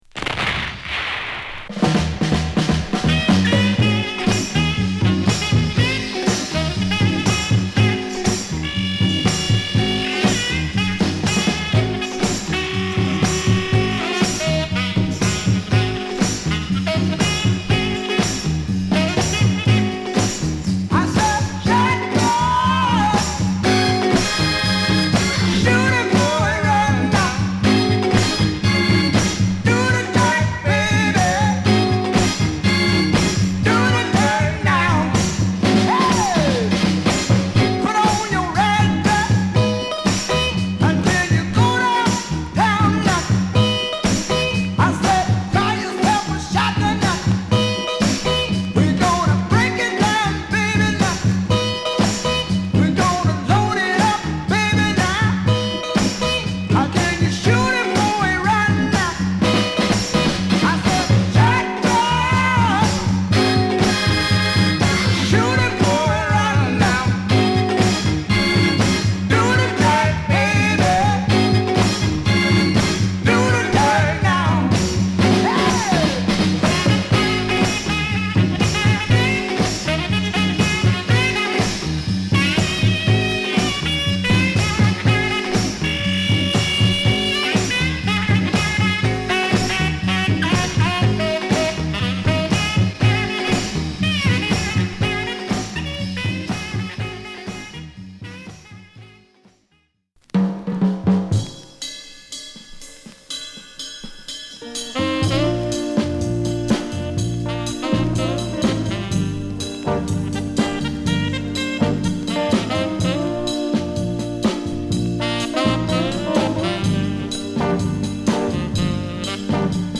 銃声のイントロから始まり、ファンキーなサックスがリードする60s R&B！！